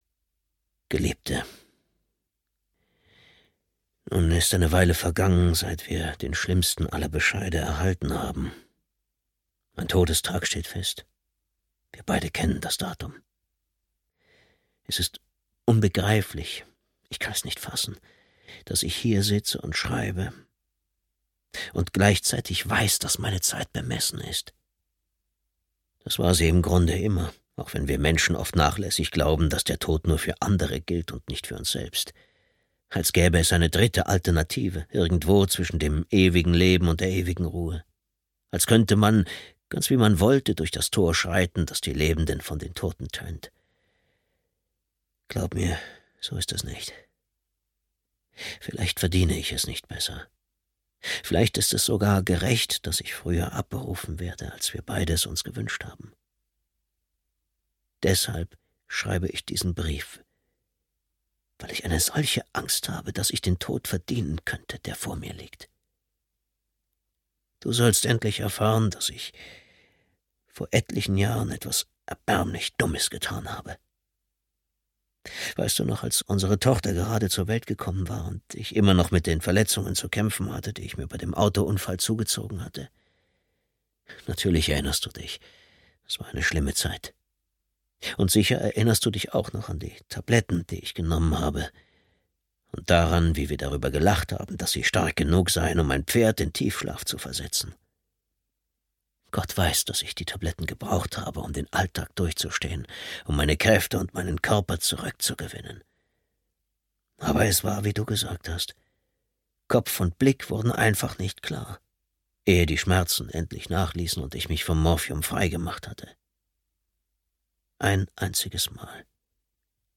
Sündengräber (DE) audiokniha
Ukázka z knihy